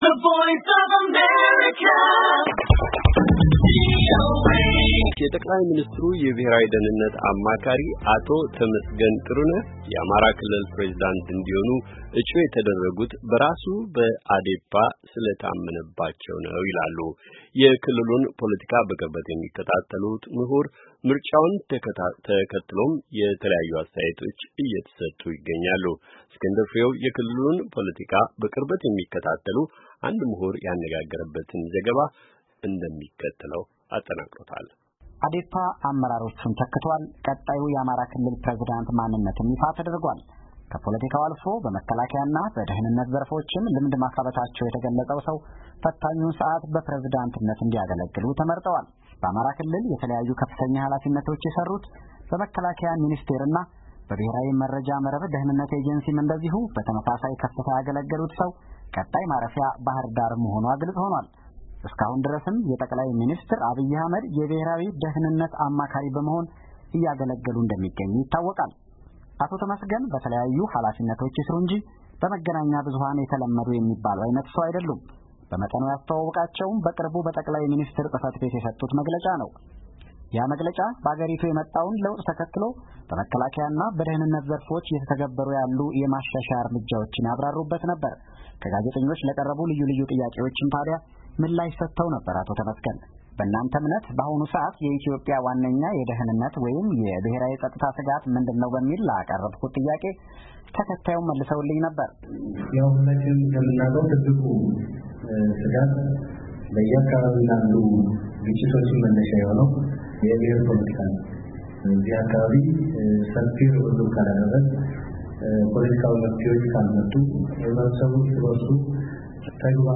የክልሉን ፖለቲካ በቅርበት የሚከታተሉ አንድ ምሁር ያነጋገረበት ዘገባ